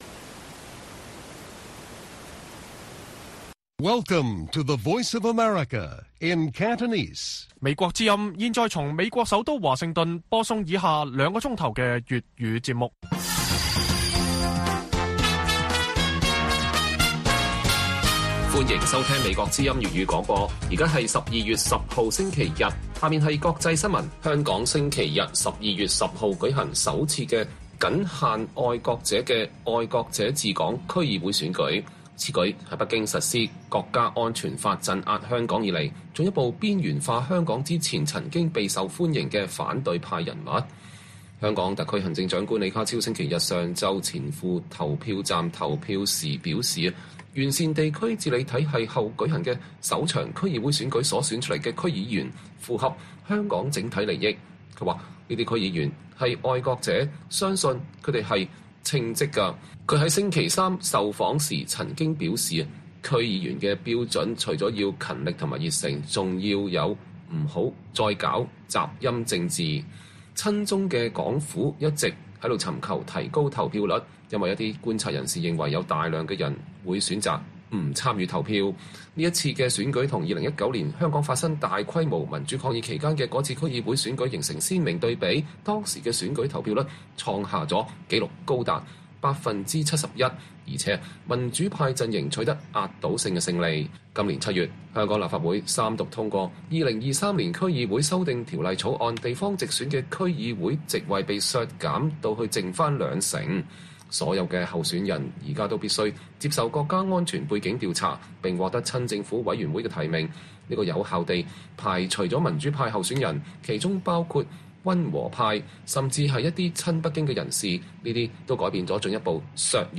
粵語新聞 晚上9-10點 : 香港舉行新制上路後的首次區議會選舉 民主派候選人全被排除